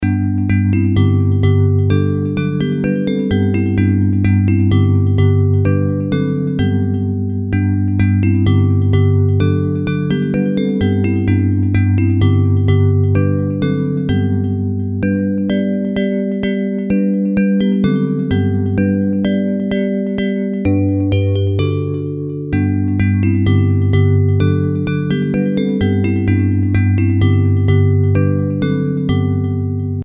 Traditional Hymns
Bells Version